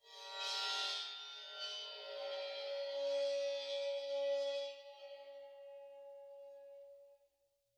susCymb1-bow-4.wav